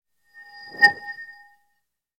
Звук джина снова улетающего внутрь волшебной лампы